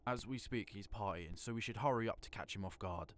Voice Lines